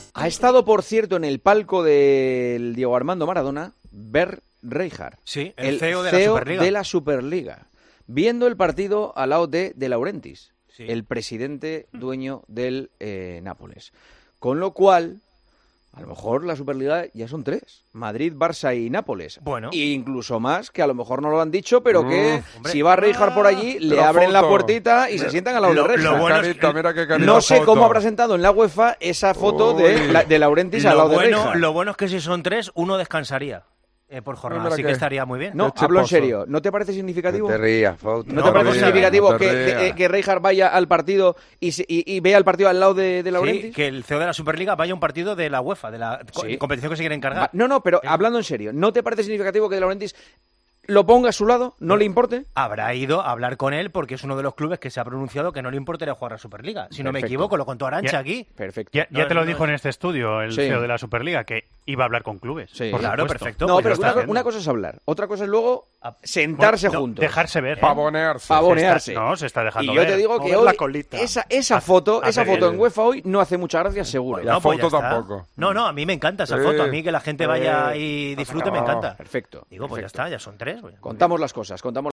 Escucha el audio de El Partidazo de COPE en el que Juanma Castaño describe la situación y la importancia de esta aparición en el palco del Diego Armando Maradona.